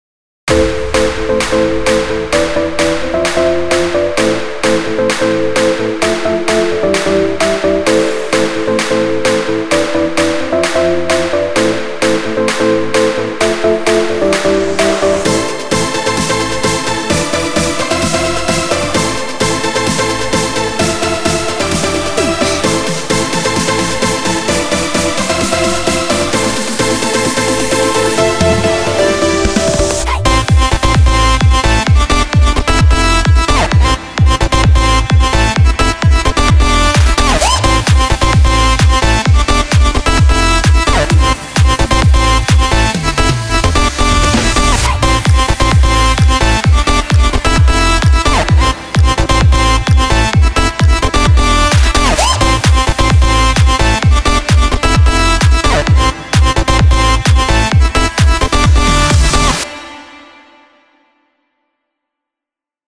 מקצבים שבניתי על קורג.